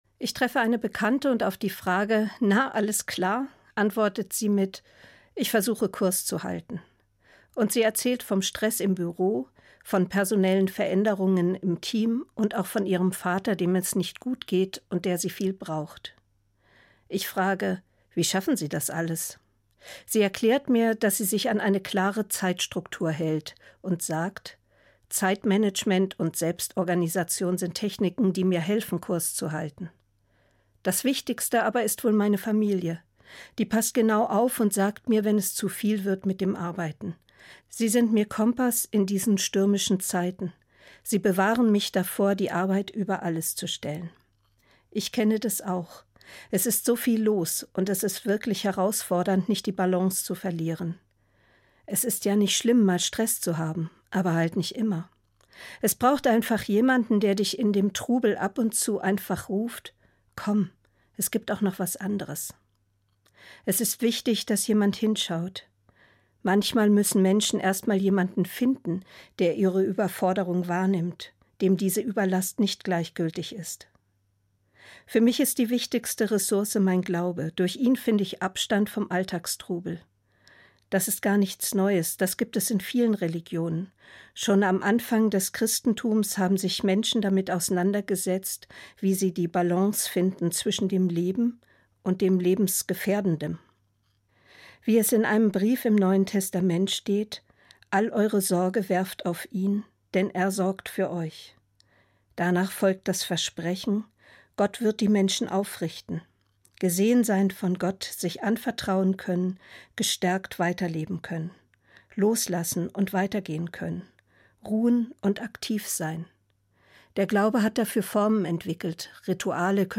Evangelische Pfarrerin, Bad Homburg